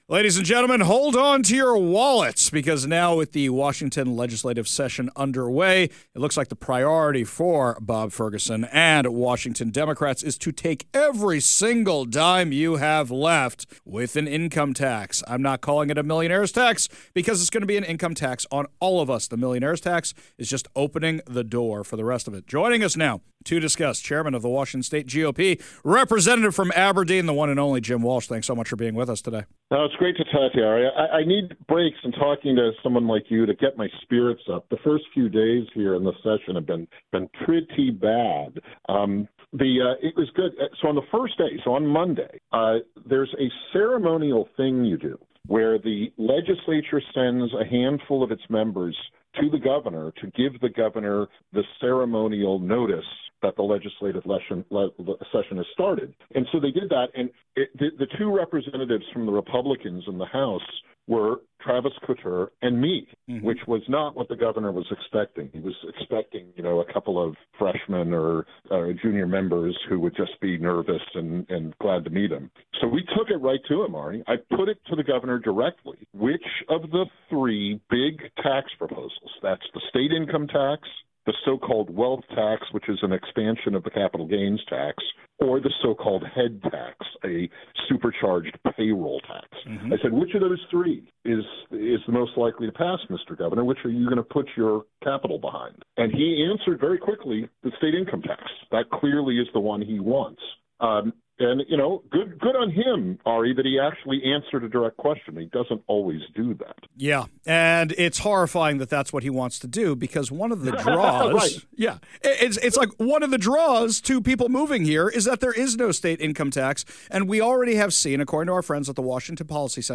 Don’t miss this eye-opening conversation on why these policies could spell economic disaster for the Evergreen State.